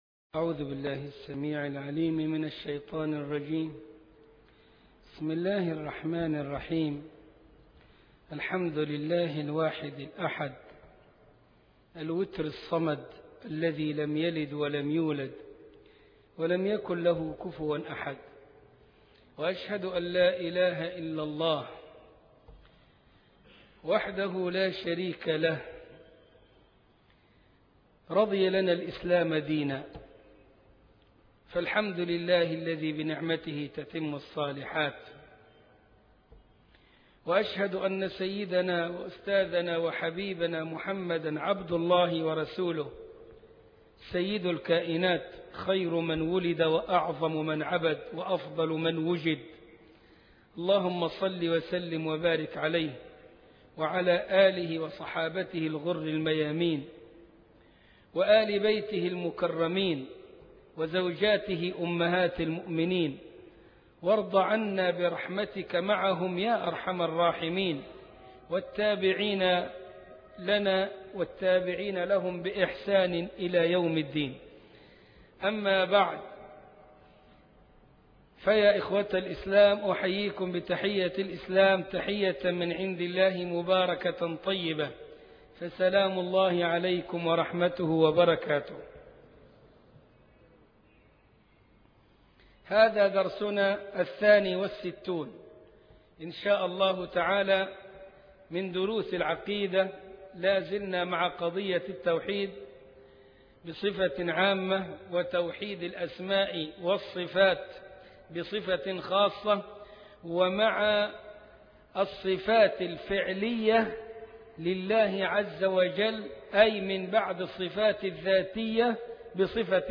عنوان المادة المحاضرة الثانية والستون (62) تاريخ التحميل الأربعاء 30 ديسمبر 2020 مـ حجم المادة 38.96 ميجا بايت عدد الزيارات 333 زيارة عدد مرات الحفظ 145 مرة إستماع المادة حفظ المادة اضف تعليقك أرسل لصديق